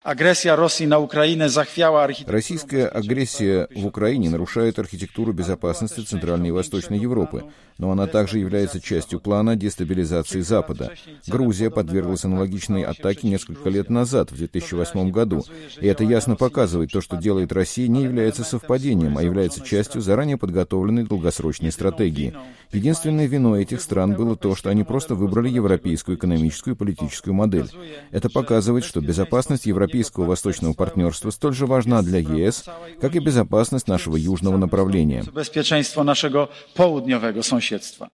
Премьер-министр Польши Матеуш Моравецкий, выступая в среду (4 июля) в Европейском парламенте в Страсбурге, предостерег Европу от строительства совместного с Россией газопровода "Северный поток – 2".